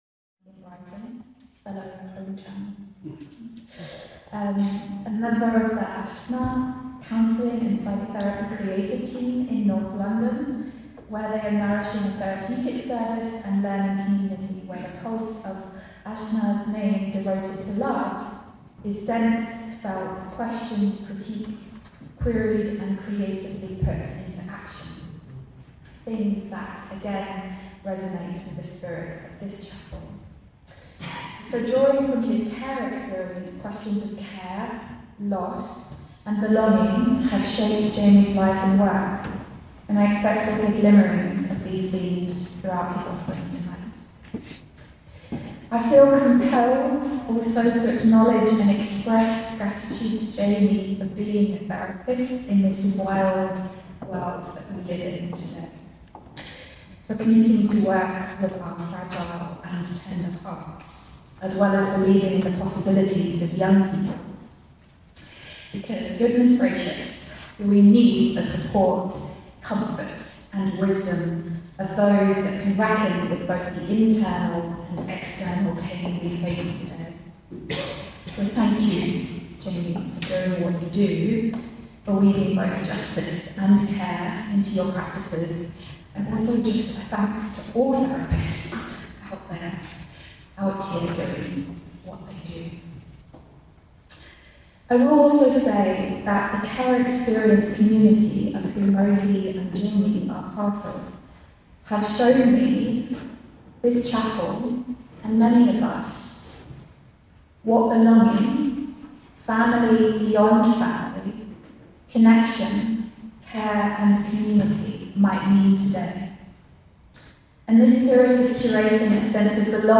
The contemplation was written to be spoken at this beautiful event, which included music, choral singing, readings and poetry.